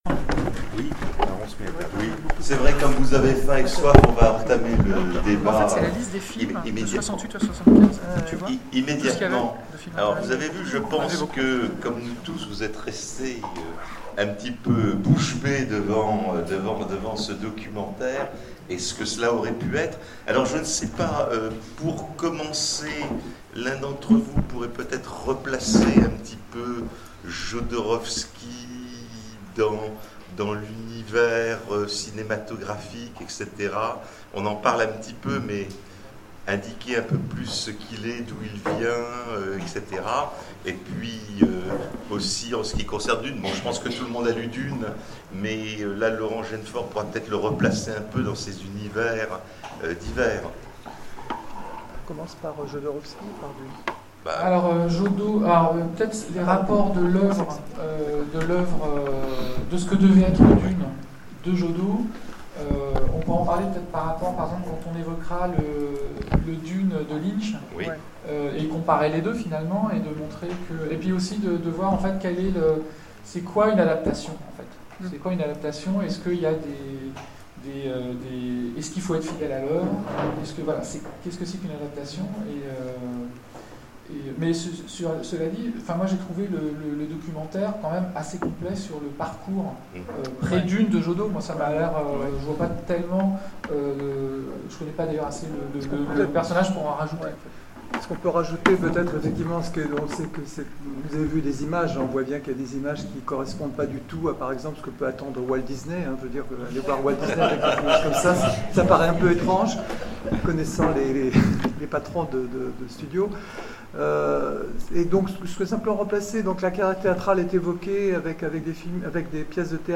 Convention SF 2016 : Conférence Jodorowsky's Dune
Conférence